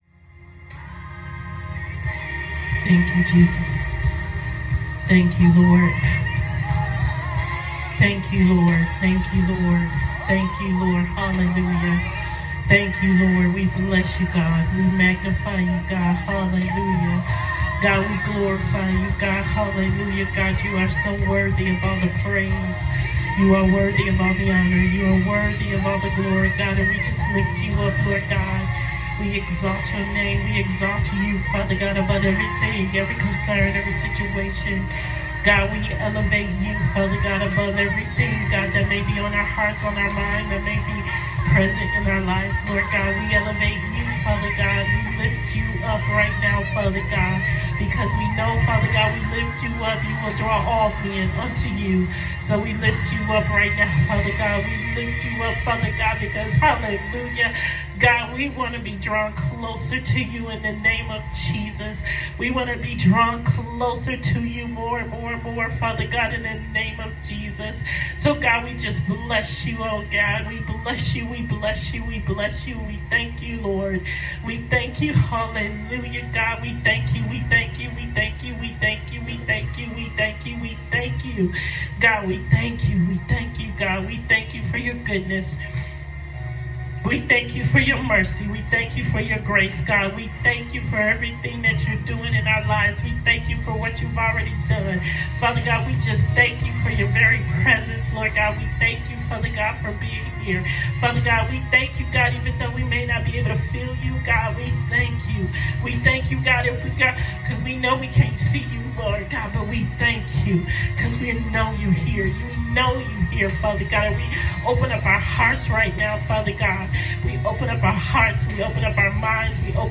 Tuesday Night Intercessory Prayer